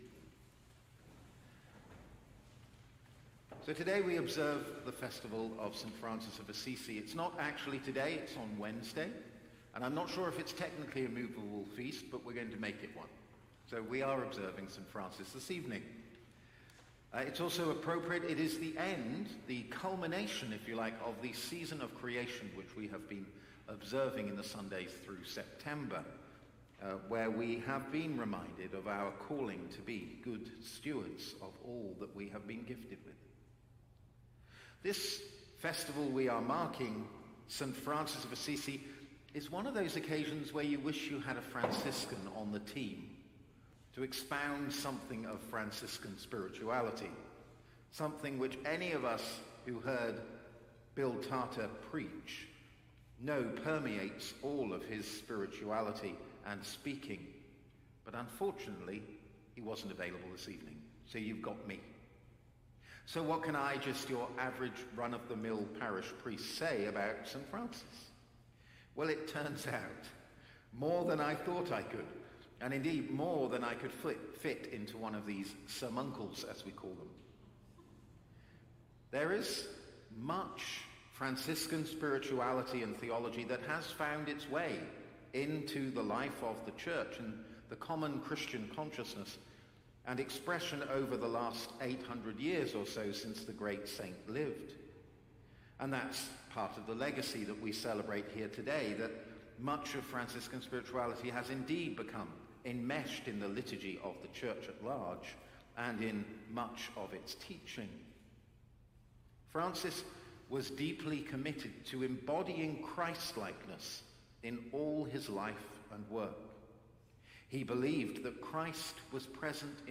Sermons | St. John the Divine Anglican Church